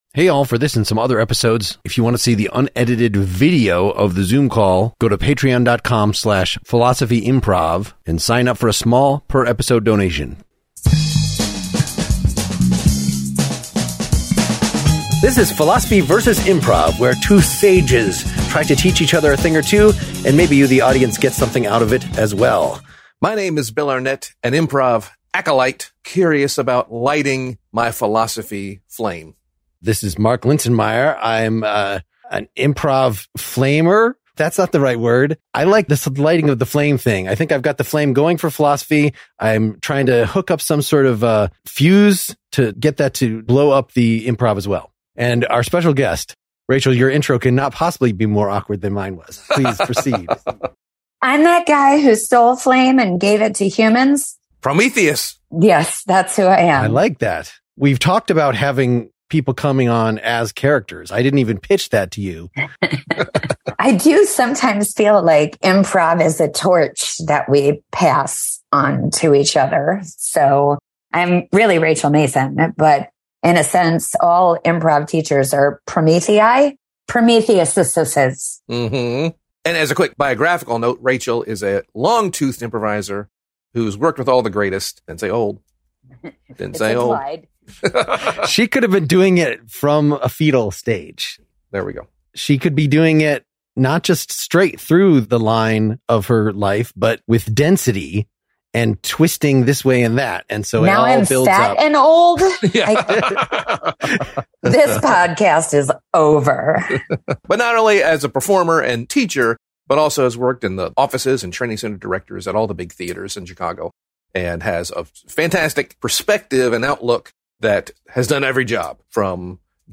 So it's like we have TWO improv instructors here. We run some scenes, talk a bit too much about chili, and touch on functionalism, idealism, napism, and other isms.